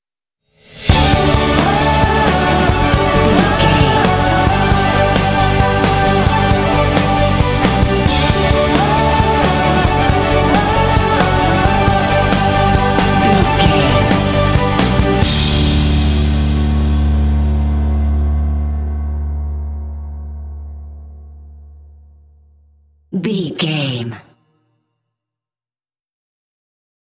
Bright and motivational music with a great uplifting spirit.
In-crescendo
Ionian/Major
D
epic
uplifting
powerful
strings
orchestra
percussion
piano
drums
rock
contemporary underscore